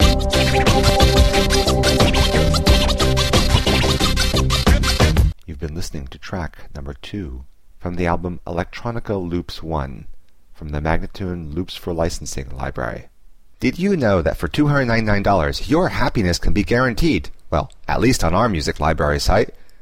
Instrumental samples in many genres.
090-C-ambient:teknology-1025